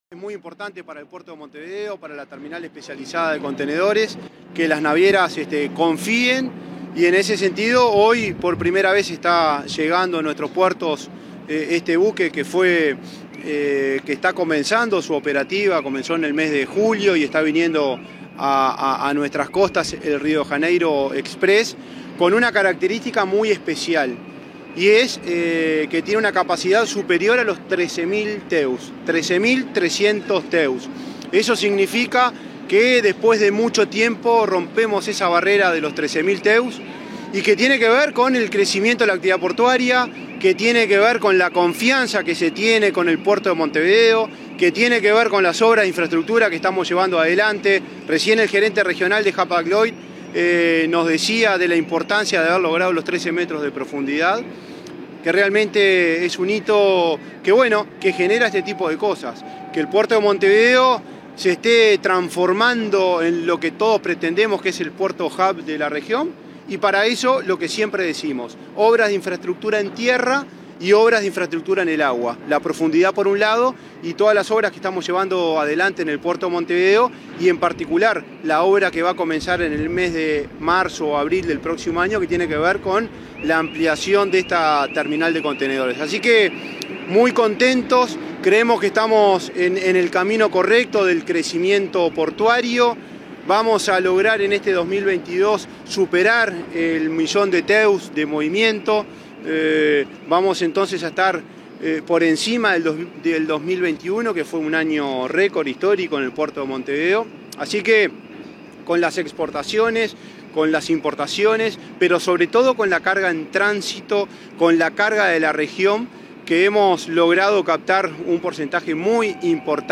Declaraciones del presidente de la ANP, Juan Curbelo
Declaraciones del presidente de la ANP, Juan Curbelo 03/11/2022 Compartir Facebook X Copiar enlace WhatsApp LinkedIn Tras la llegada del buque de 335 metros de eslora y 51 metros de manga de la naviera de origen alemana Hapag Lloyd, la quinta en el mundo, el presidente de la Administración Nacional de Puertos, Juan Curbelo, realizó declaraciones a la prensa.